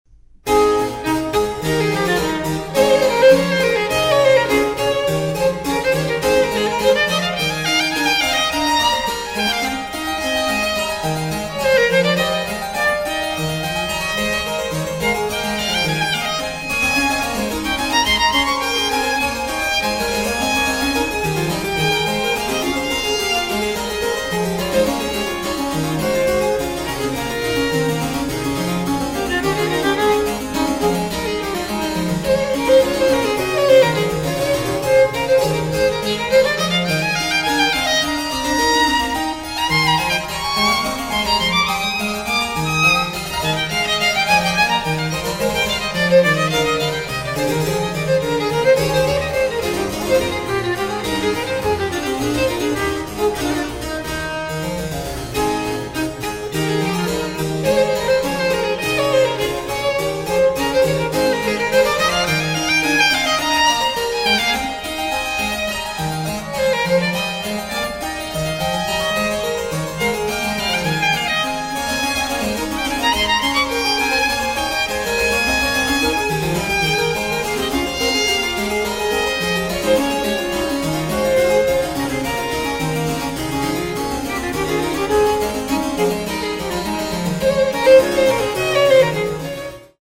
Sonate per Violino e Clavicembalo BWV 1014-1019
harpsichord